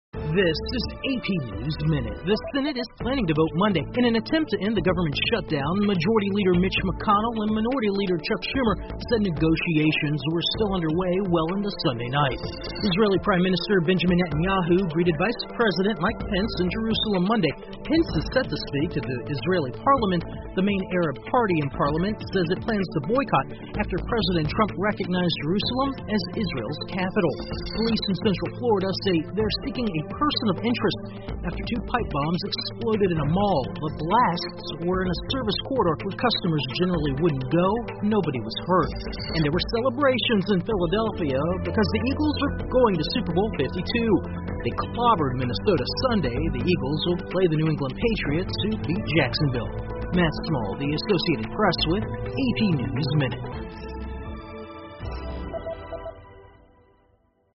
美联社新闻一分钟 AP 老鹰队杀进超级碗 听力文件下载—在线英语听力室